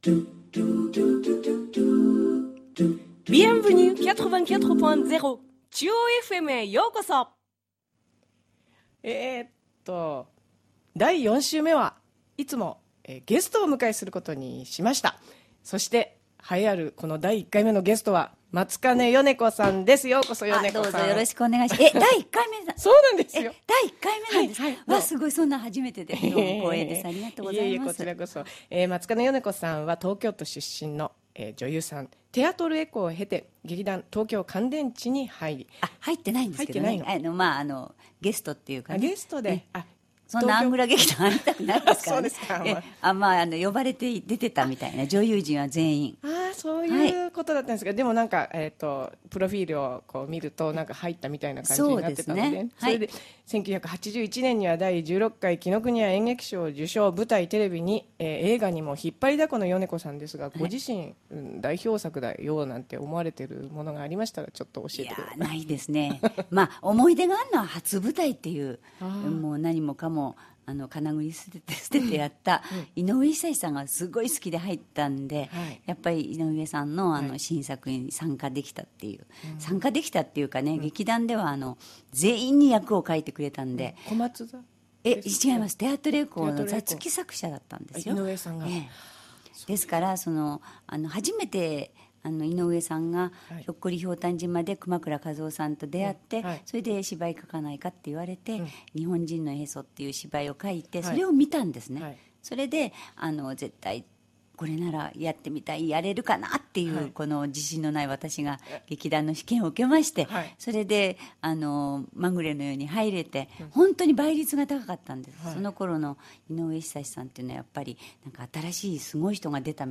4月24日放送にゲストに来ていただいた松金よね子さんとのトーク部分をお裾分けです！
残念ながら松金さんおすすめの曲の部分はカットしています。